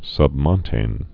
(sŭbmŏntān, -mŏn-tān)